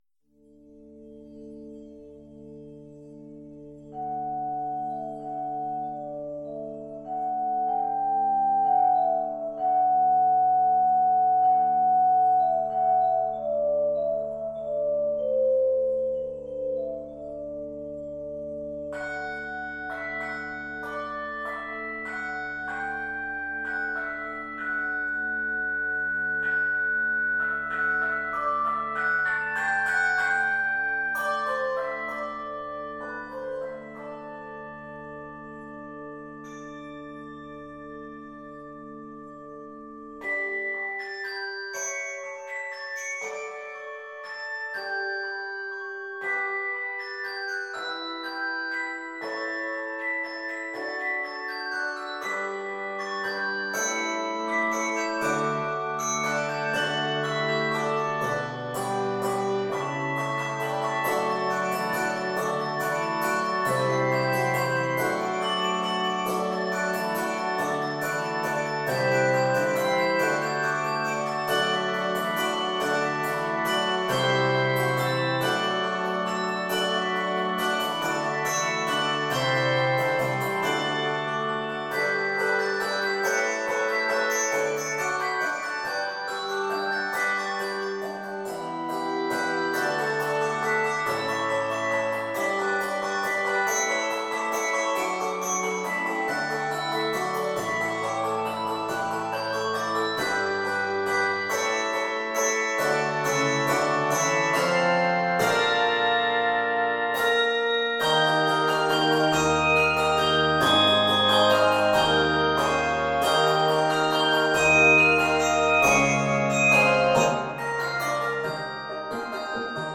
No. 1- Fanfare
Aria